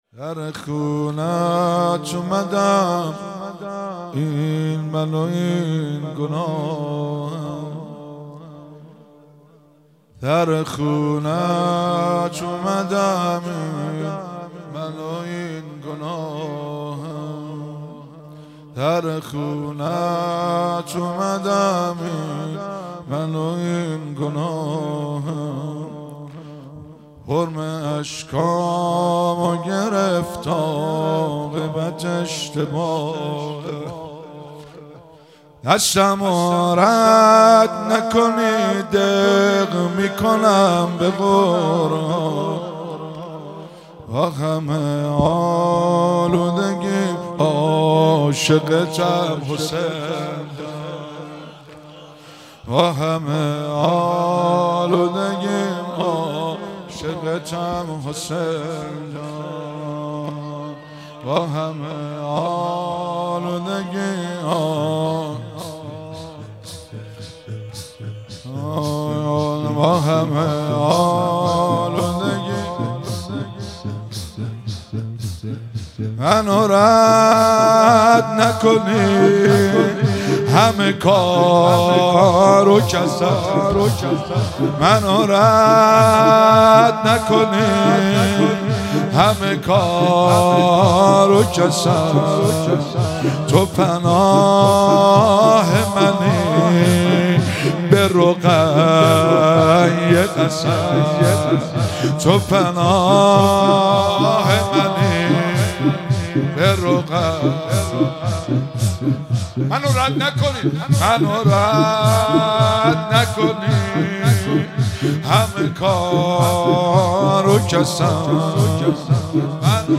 مراسم مناجات خوانی شب پنجم ماه رمضان 1444
سینه زنی- در خونه ات اومدم این من و این گناهم